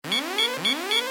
MissileWarn.wav